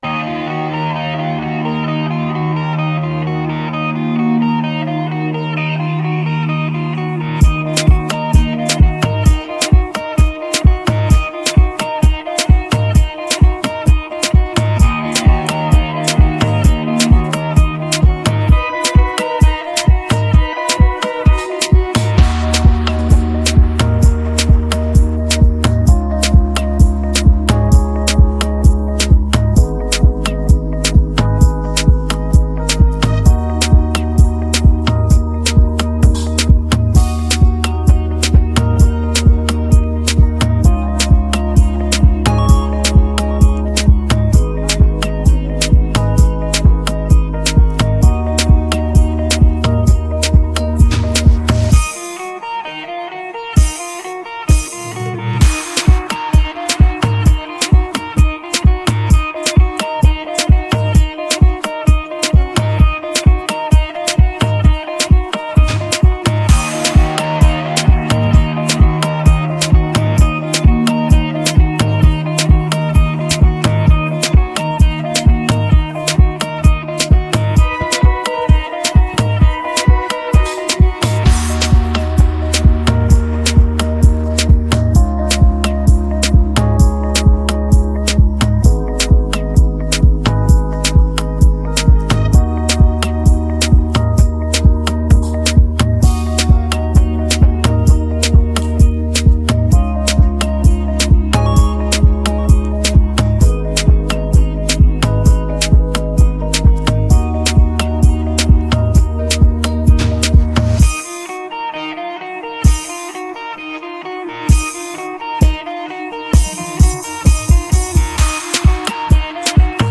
Afro popAfro trapAfrobeats